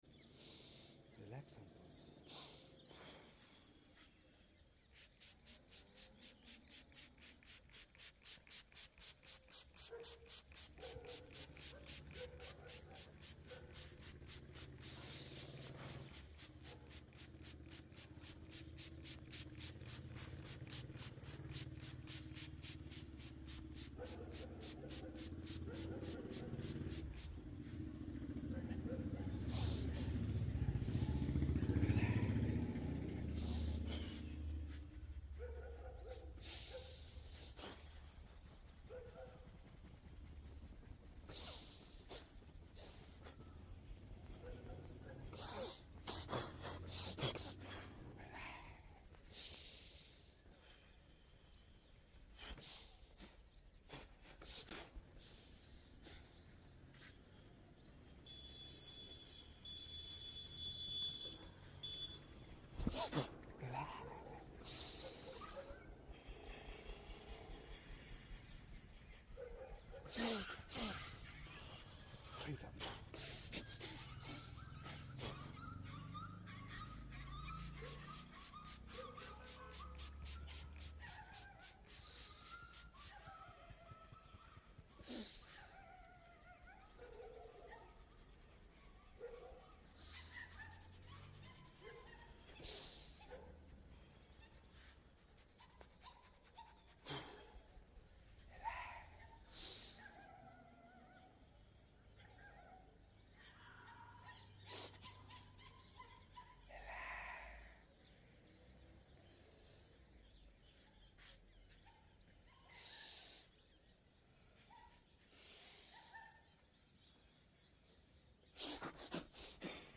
These Soothing Sounds Will Put You To Sleep… FAST!